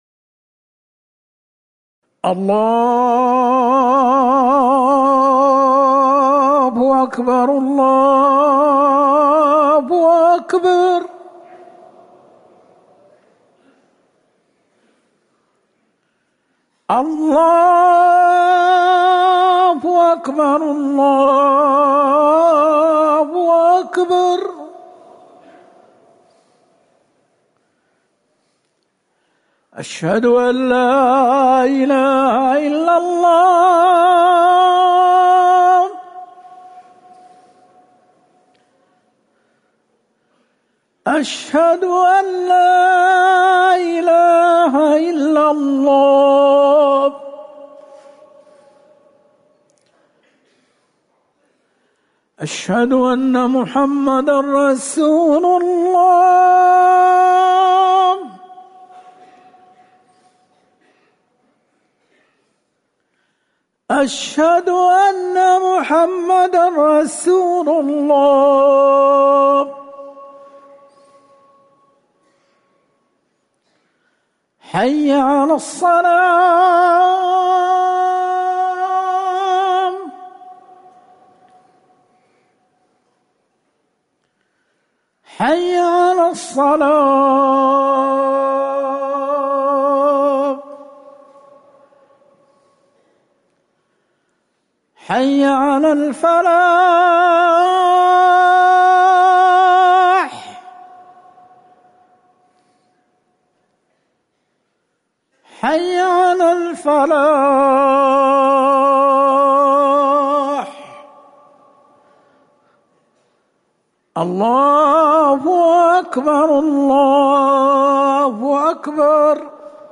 أذان الفجر الأول - الموقع الرسمي لرئاسة الشؤون الدينية بالمسجد النبوي والمسجد الحرام
تاريخ النشر ٩ صفر ١٤٤١ هـ المكان: المسجد النبوي الشيخ